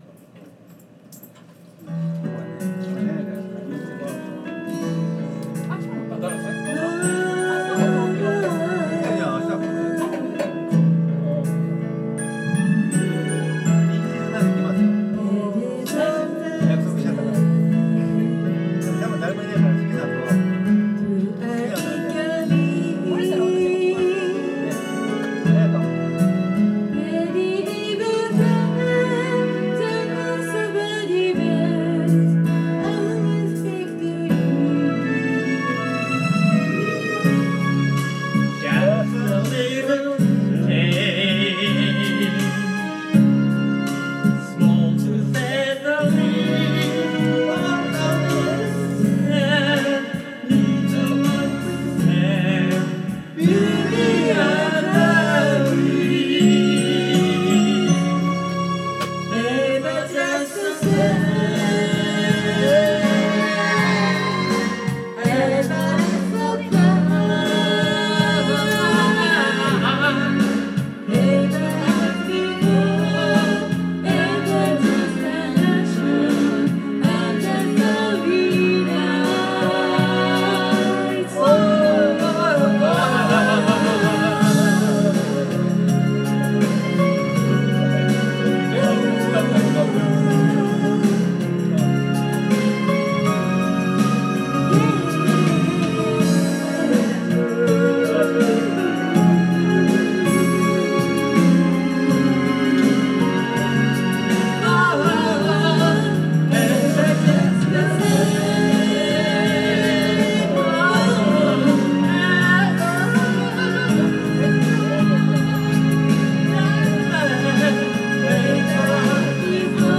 Duet & Chorus Night Vol. 13 TURN TABLE